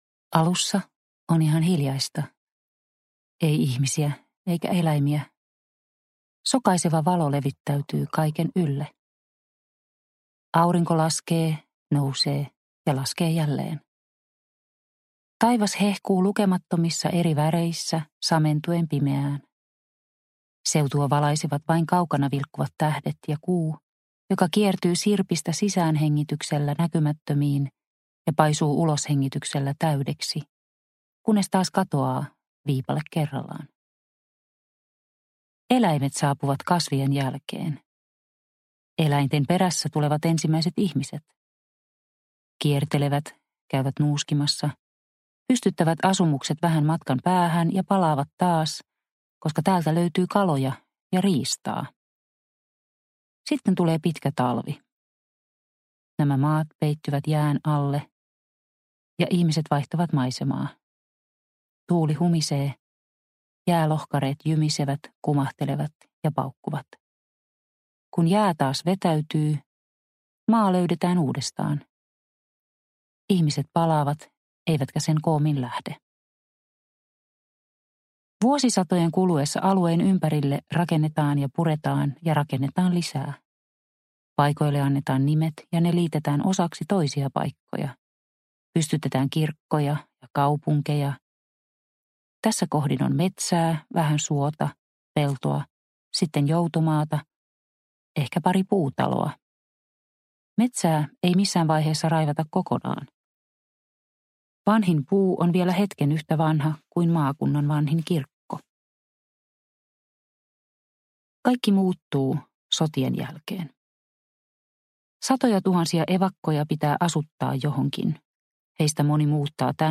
Kerrostalo – Ljudbok – Laddas ner